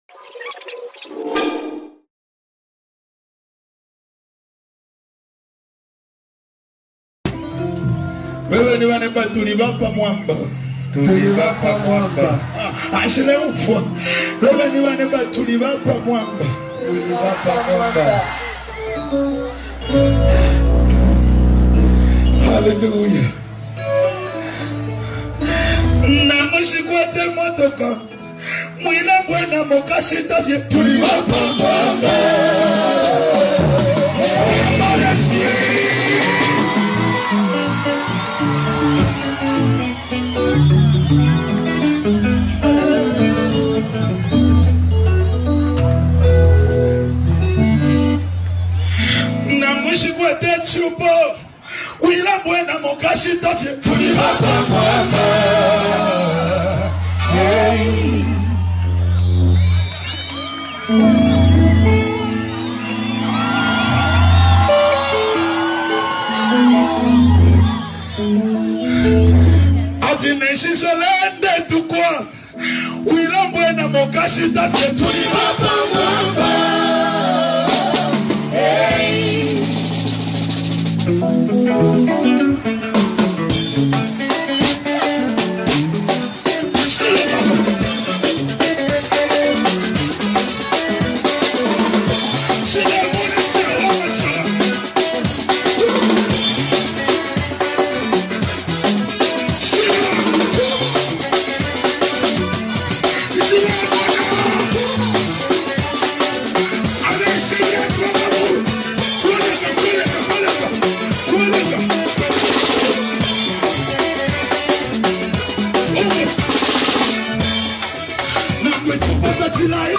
vibrant Rumba Gospel anthem